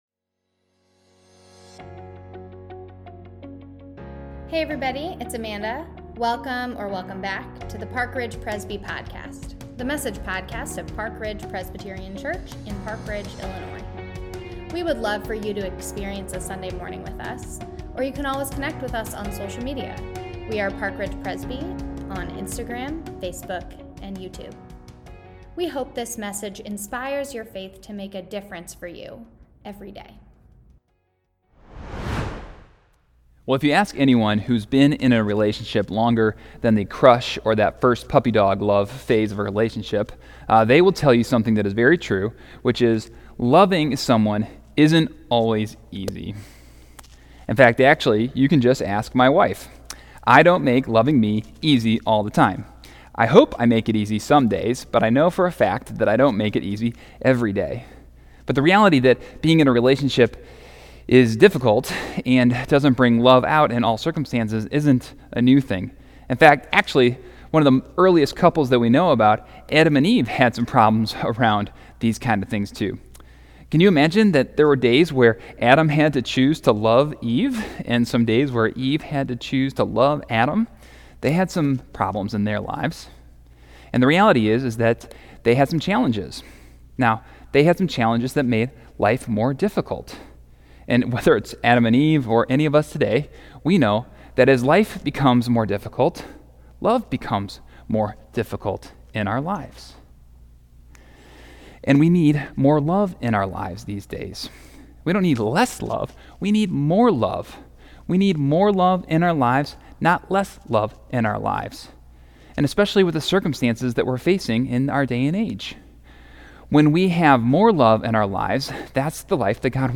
Feb14SermonAudio-2.mp3